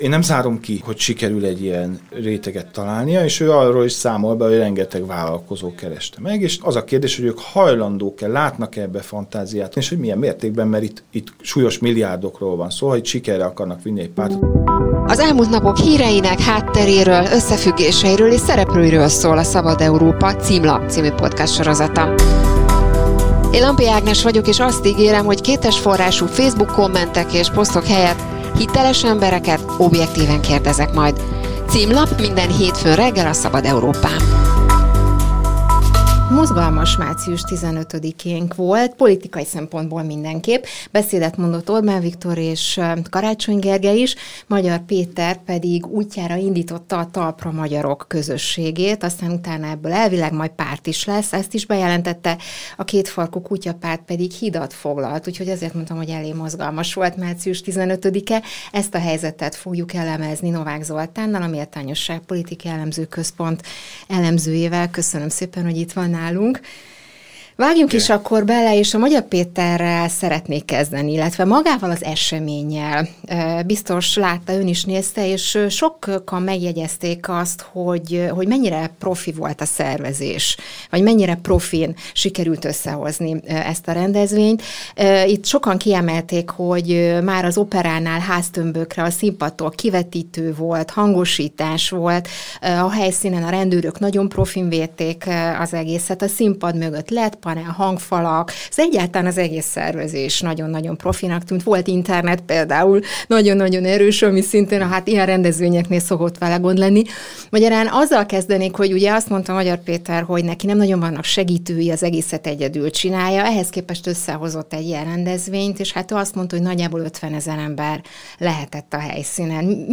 Magyar Péter retorikai képességei nem erősek, emocionálisan mégis bevonta a közönségét. Elemzés az ünnepről.